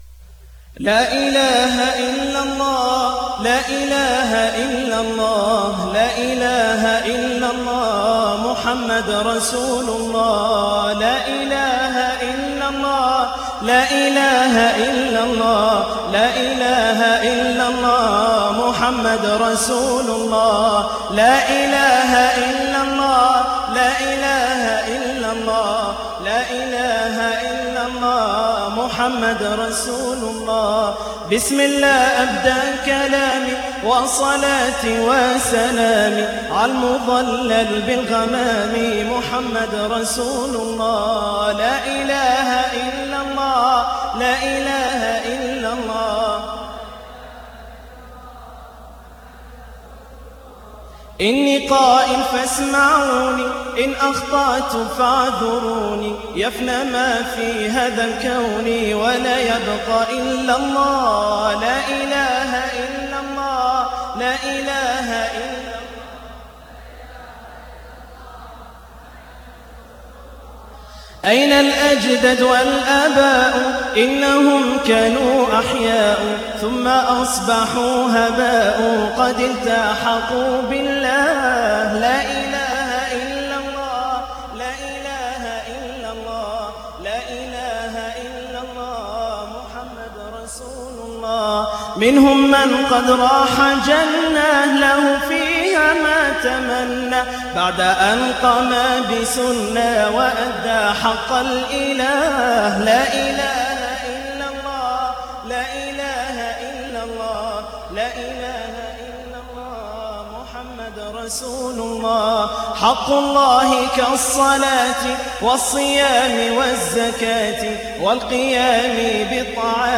مبدع أيما إبداع ويتمتع بمساحة صوتية عريضه .. وعُرب جبلي ..